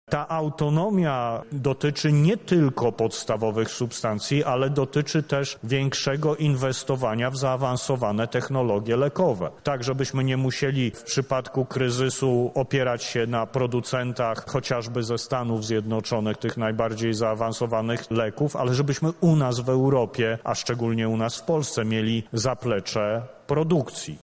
Powiedział o tym minister zdrowia Adam Niedzielski podczas Kongresu Trójmorza.
To nie jest tylko doświadczenie polskie i pora to zmienić – powiedział minister zdrowia Adam Niedzielski: